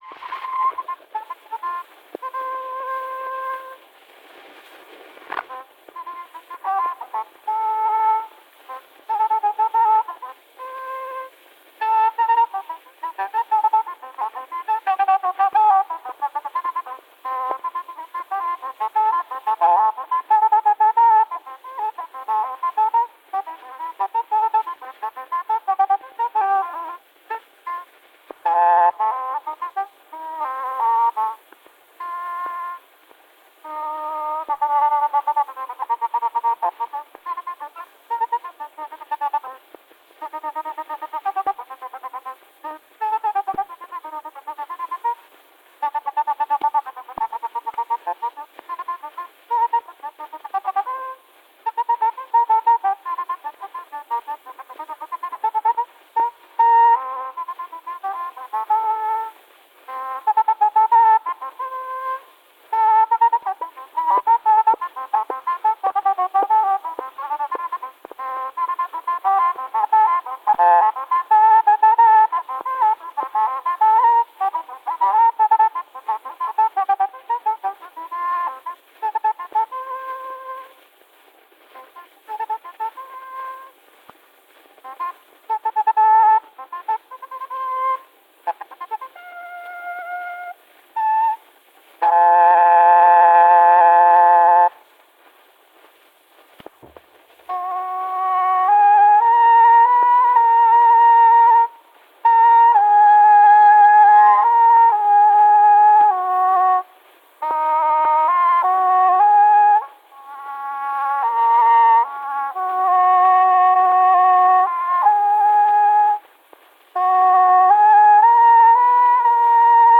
[To kompositioner spillet på obo d'amore eller engelskhorn.]
To komplette kompositioner højst sandsynligt spillet på obo d'amore eller engelskhorn.